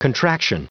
Prononciation du mot contraction en anglais (fichier audio)
Prononciation du mot : contraction